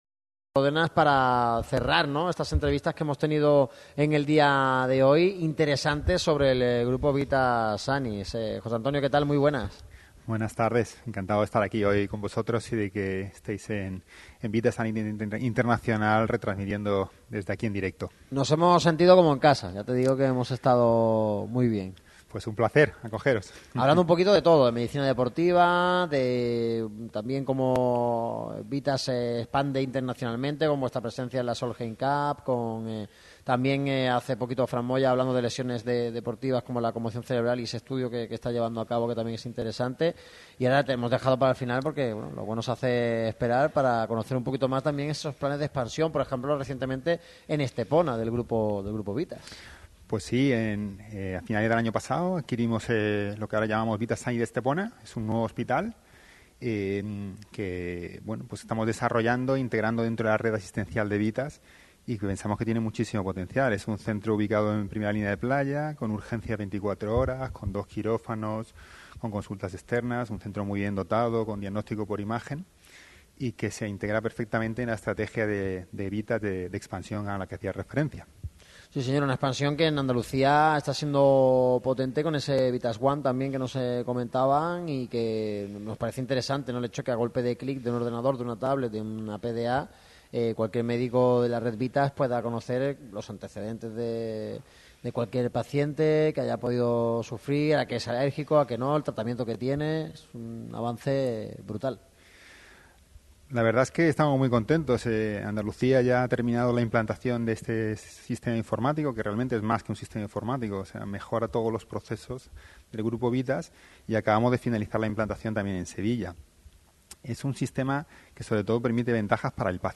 El micrófono rojo de Radio MARCA Málaga se desplaza hoy a las instalaciones de Vithas Xanit Salud en Av. de los Argonautas, en la localidad malagueña de Benalmádena. Seguimos contando con la presencia de los grandes profesionales del Grupo Xanit, donde toca hablar de salud pero también de la evolución, crecimiento y gran experiencia que atesora.